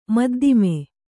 ♪ maddime